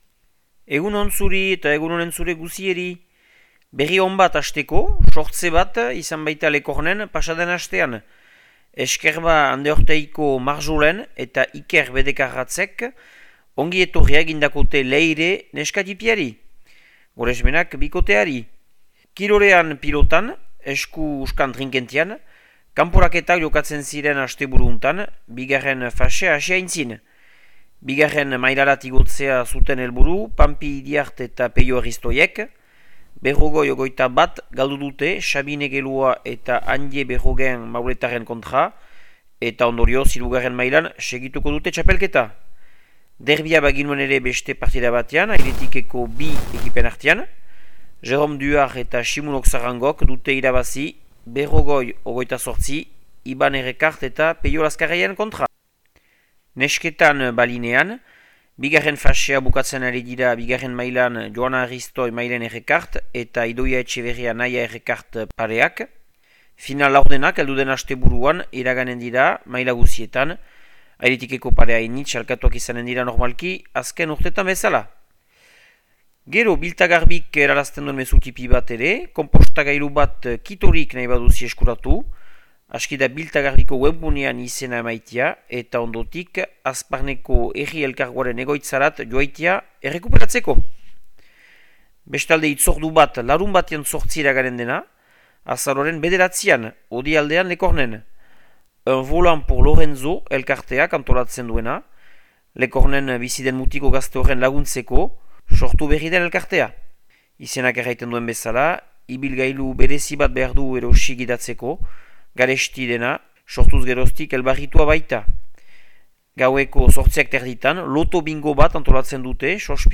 Urriaren 28ko Makea eta Lekorneko berriak